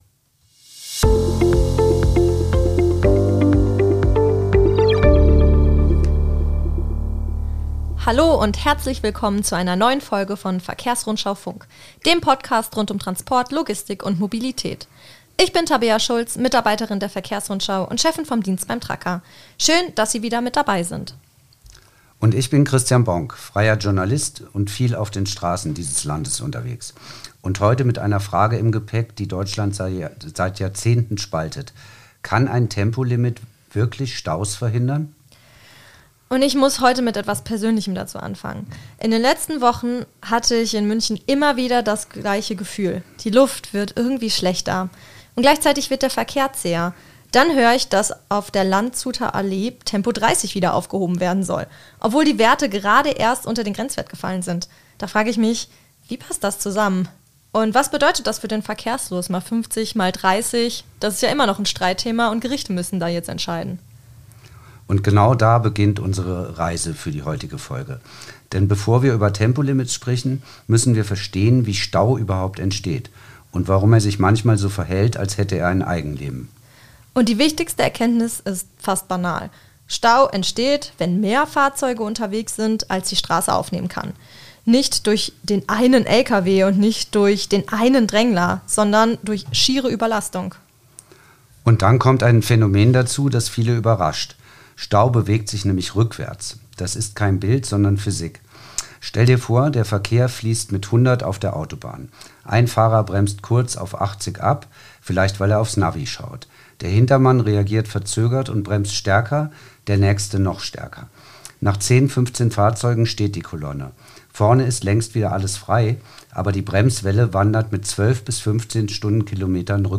Außerdem werfen sie einen Blick auf internationale Vergleiche, die Rolle von Mautsystemen und die Frage, welche Wirkung Tempolimits tatsächlich auf den Verkehrsfluss haben. Im Interview